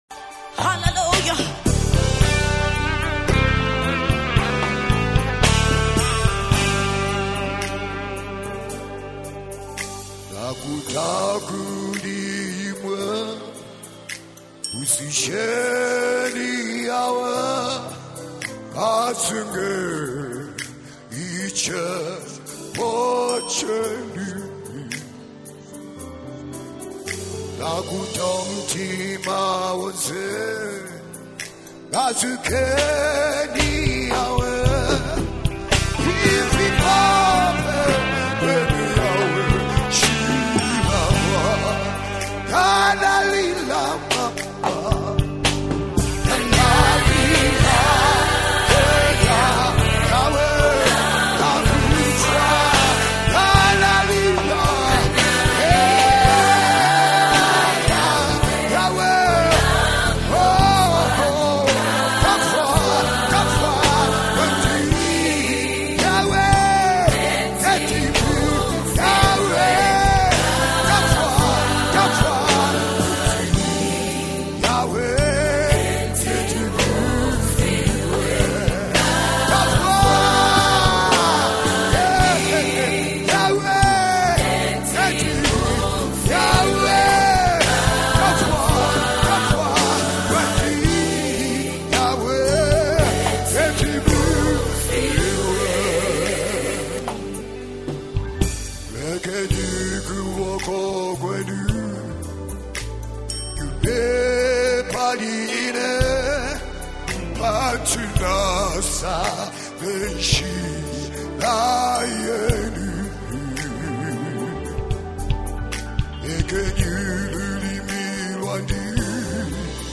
PASSIONATE and ANNOINTED PERFORMANCE
🎼 GENRE: ZAMBIAN GOSPEL MUSIC